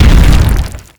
s_effect_stone_hit_0011.wav